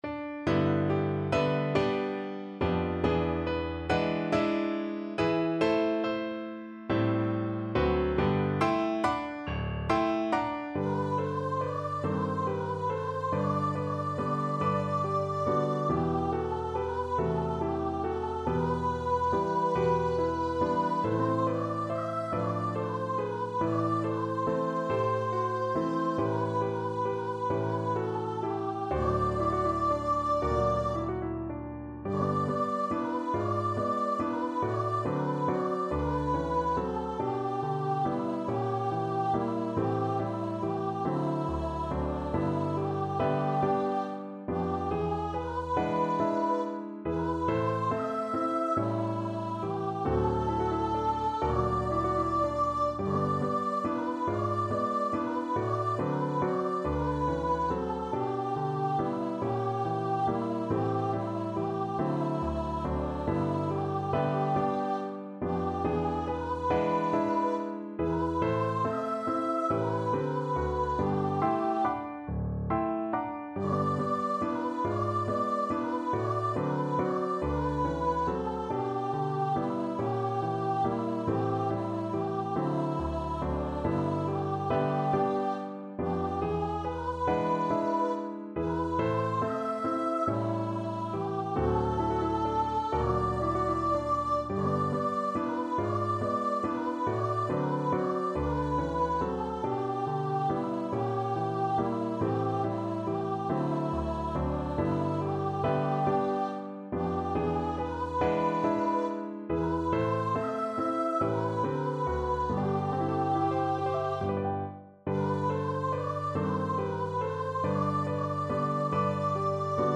3/4 (View more 3/4 Music)
~ = 140 Tenderly
Pop (View more Pop Voice Music)